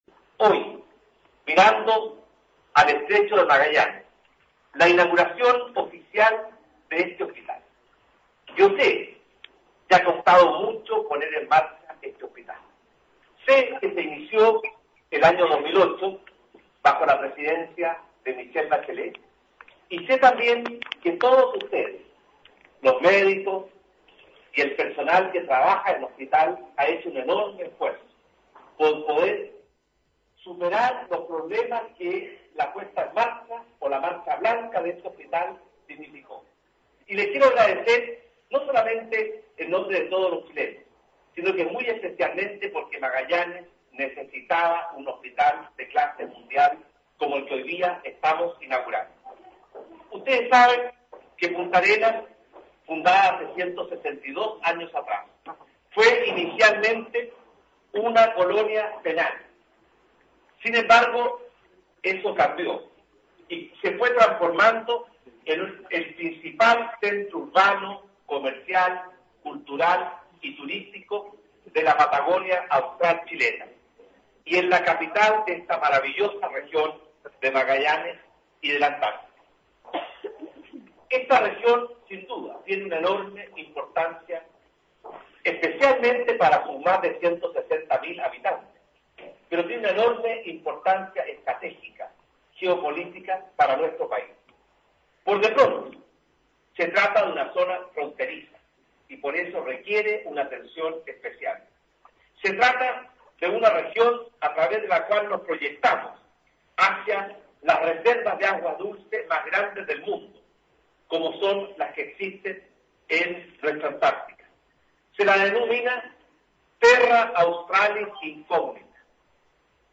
Entrevistas de Pingüino Radio - Diario El Pingüino - Punta Arenas, Chile
Arturo Storaker, intendente de Magallanes